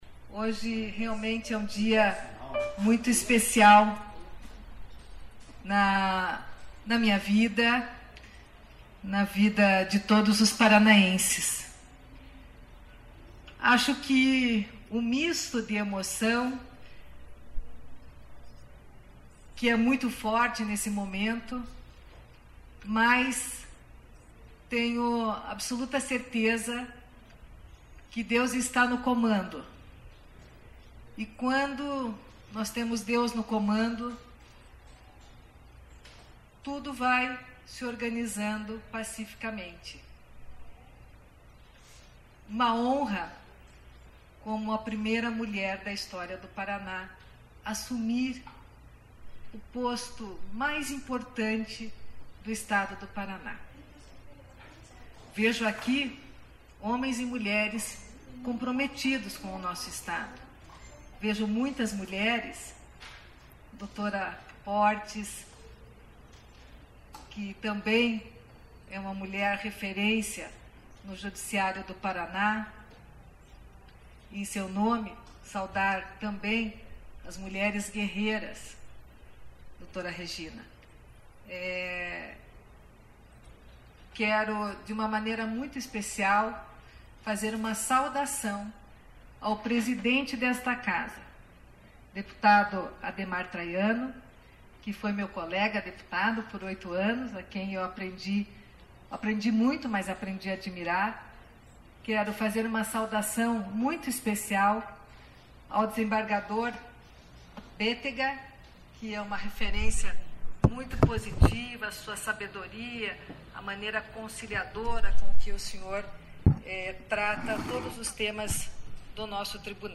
Ouça primeiro discurso de Cida Borghetti como governadora do Paraná
Ouça o discurso na íntegra.(Sobe som)))